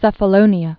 (sĕfə-lōnē-ə, -lōnyə) also Ke·fal·li·ní·a (kĕfä-lē-nēä)